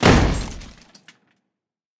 Metal impact 5.wav